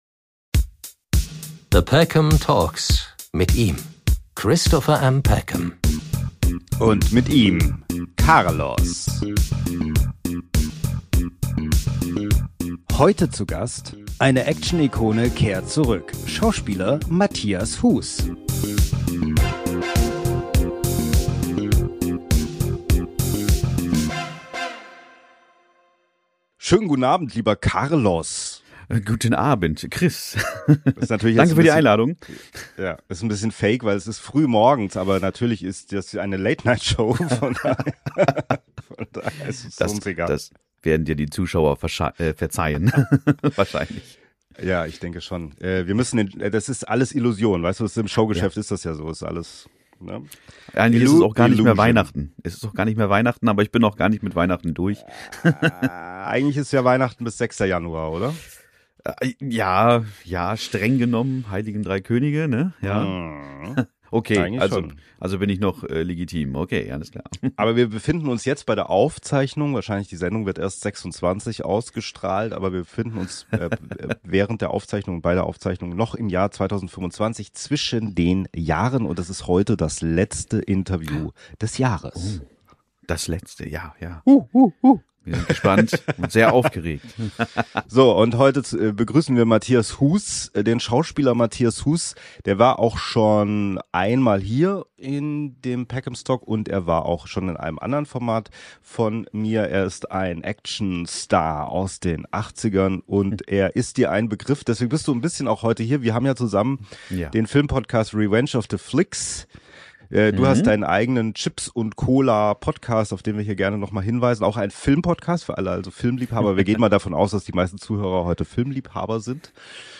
Wie war es damals in Hollywood - und wie ist es heute, was hat sich (zum negativen) verändert? Ein Gespräch zwischen Nostalgie und Kritik.